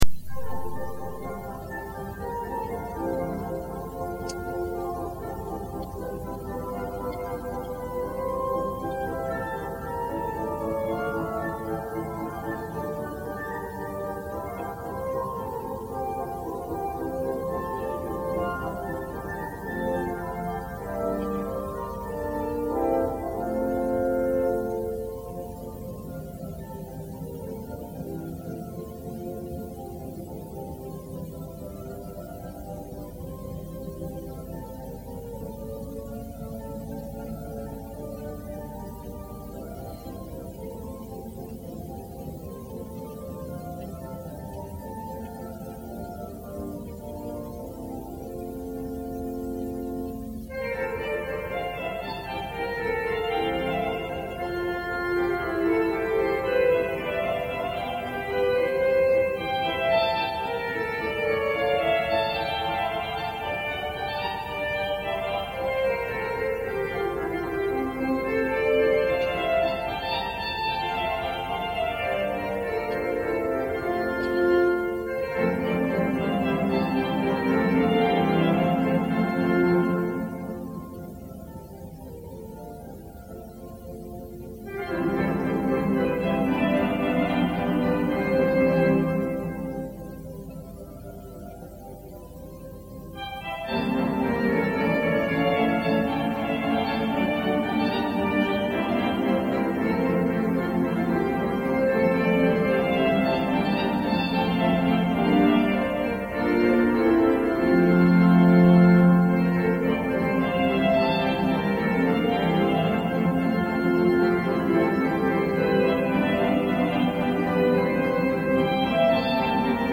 de sus dos conciertos en el gran órgano del Palacio Nacional de Montjuich.